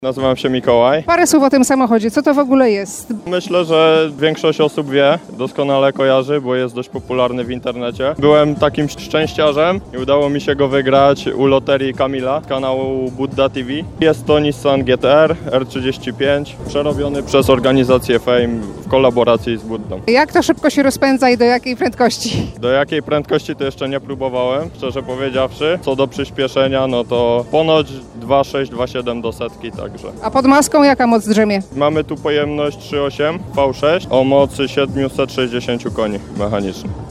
Ryk silników, pisk opon i maszyny takie, że dech zapiera. W hali pod Dębowcem w Bielsku-Białej trwa Moto Show 2023 – największa impreza motoryzacyjna w tej części Europy.